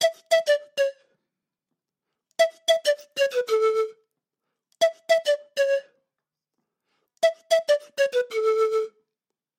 Quena竹笛拉丁语循环播放4
标签： 100 bpm Ethnic Loops Flute Loops 207.78 KB wav Key : A Logic Pro
声道单声道